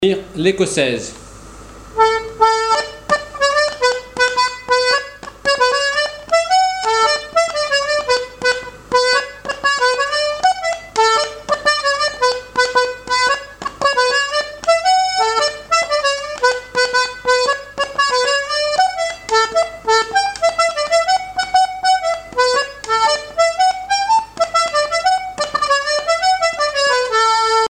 airs de danse à l'accordéon diatonique
Pièce musicale inédite